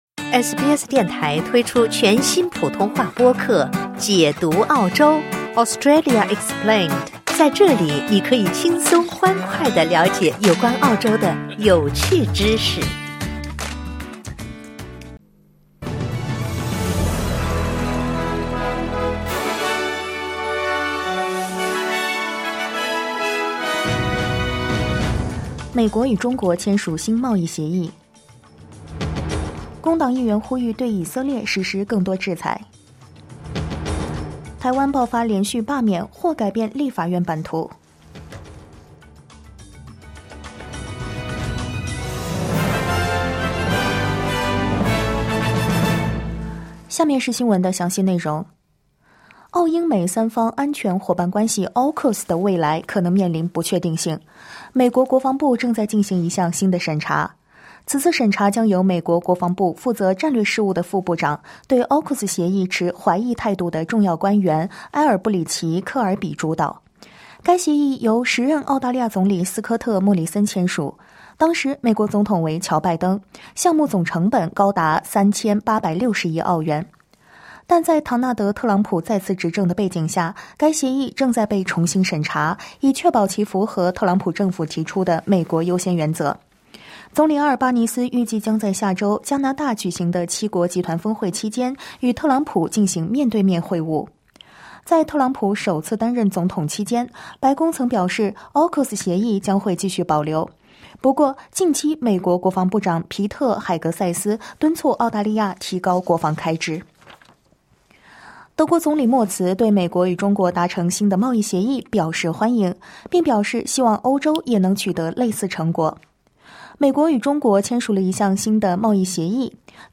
SBS早新闻（2025年6月12日）